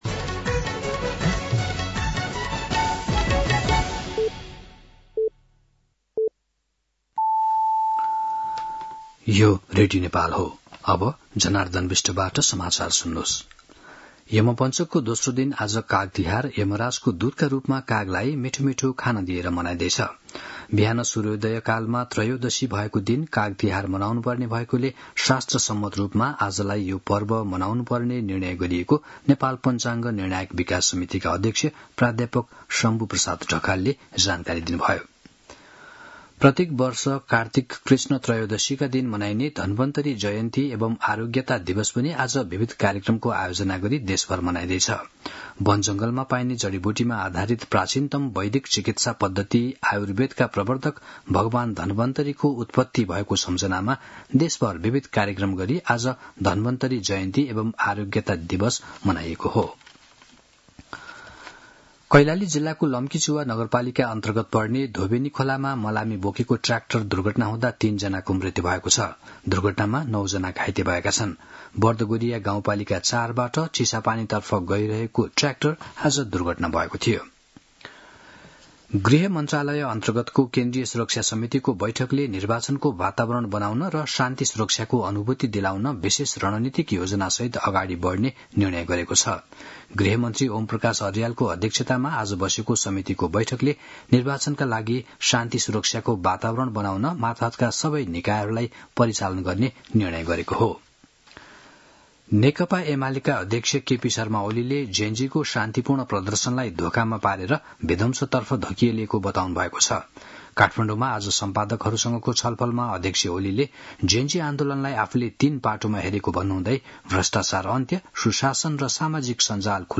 साँझ ५ बजेको नेपाली समाचार : २ कार्तिक , २०८२
5.-pm-nepali-news-1-4.mp3